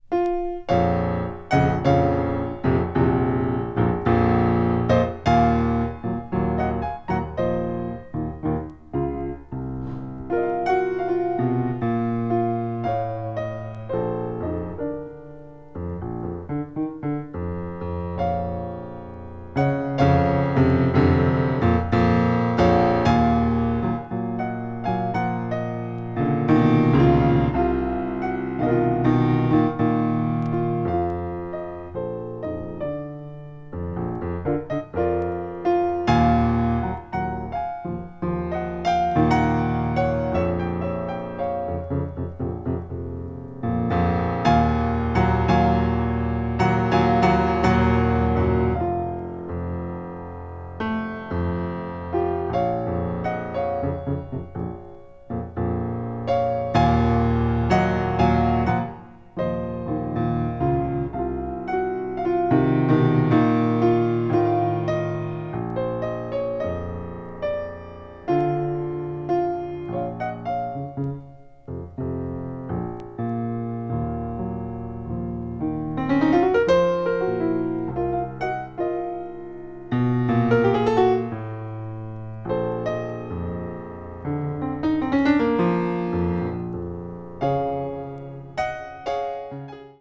media : EX/EX(わずかにチリノイズが入る箇所あり)
感情の赴くままに美しく繊細、かつエネルギッシュな旋律を即興的に紡ぎ上げた、全くもって素晴らし過ぎる演奏。
contemporary jazz   piano solo   post bop